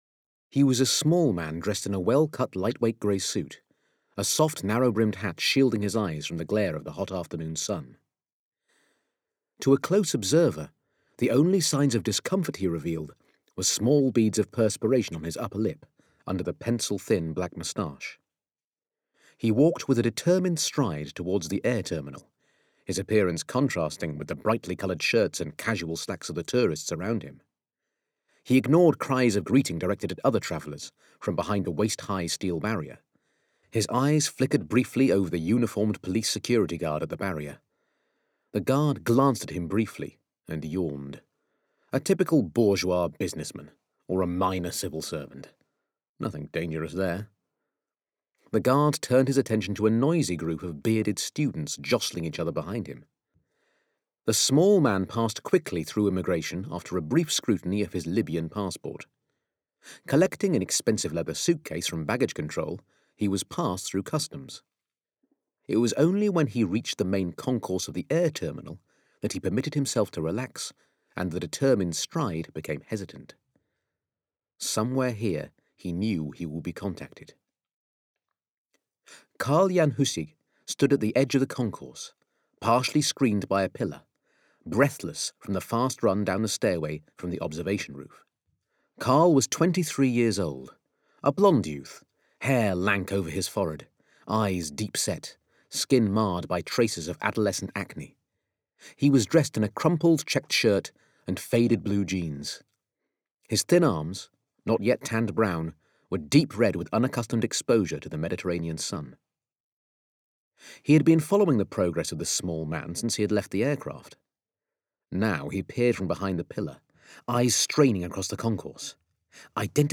Big Finish Audiobooks The Assassination Run